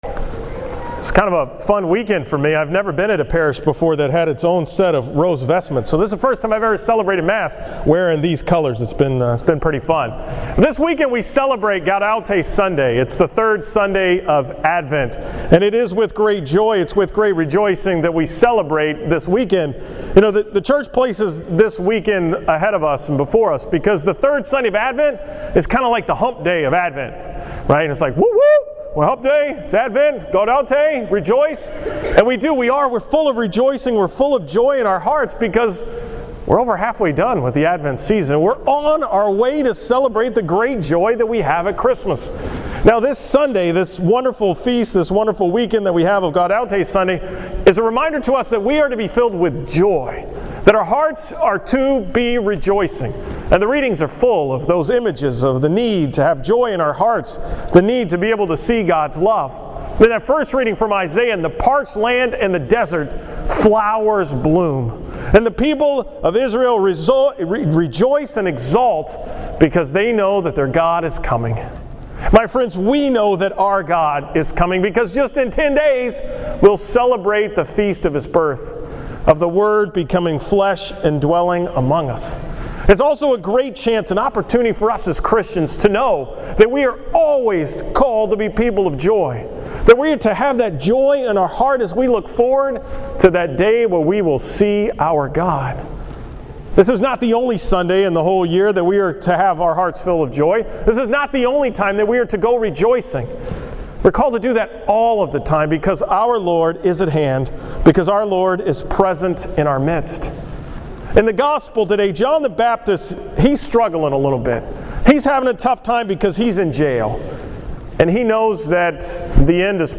From the Third Sunday of Advent
Category: 2013 Homilies